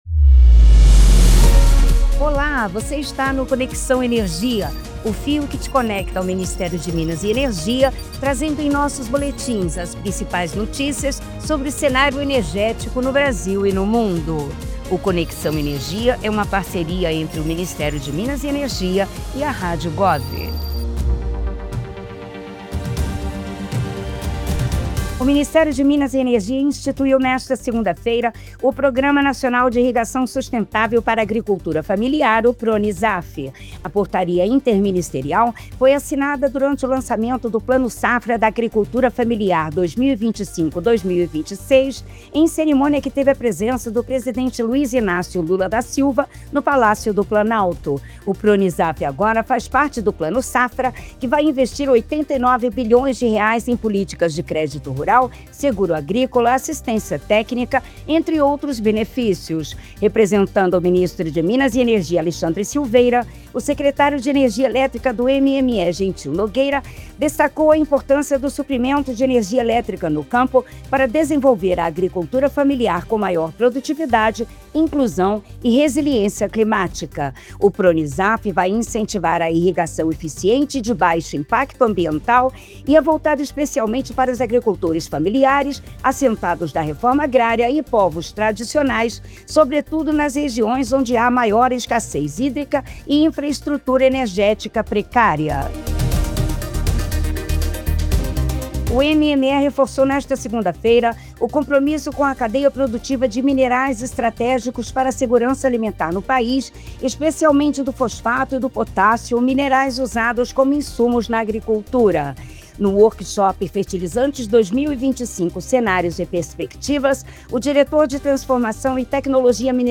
Convocação de Rede
01/10/22 - Pronunciamento do Presidente do TSE, Ministro Alexandre de Moraes